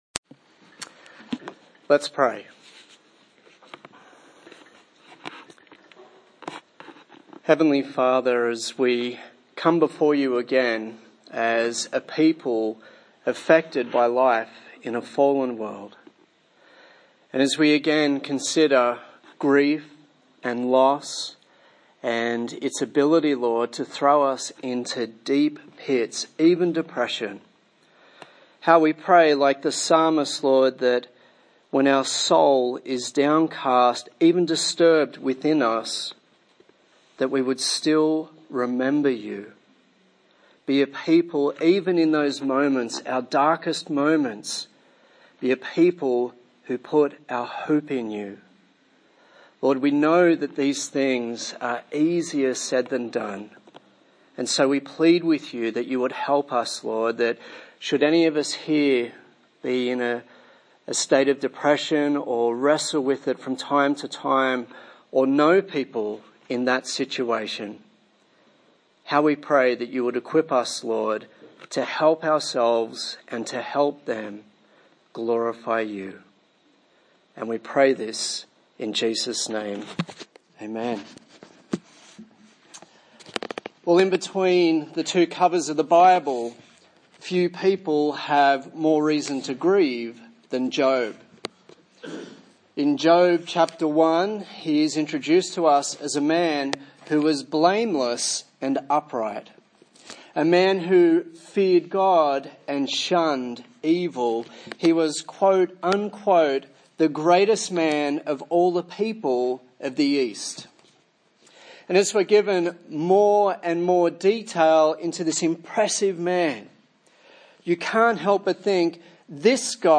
A sermon in the series on Grief